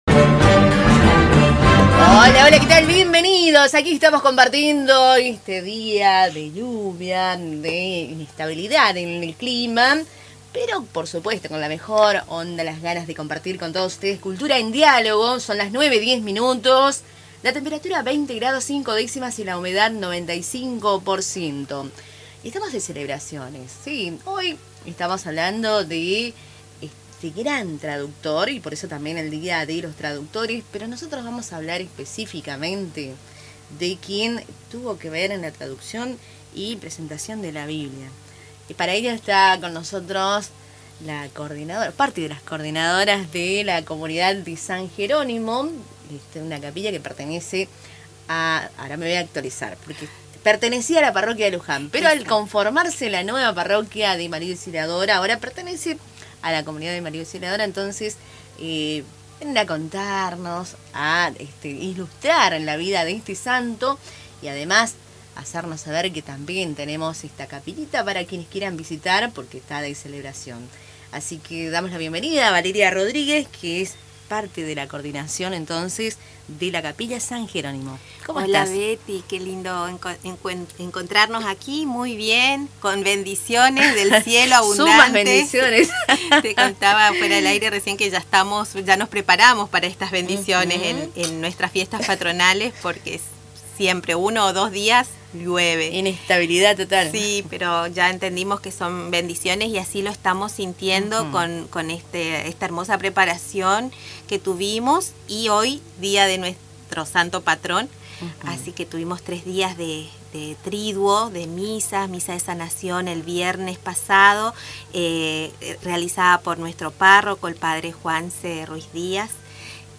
relató en Radio Tupambaé los detalles de la celebración y la historia de la comunidad.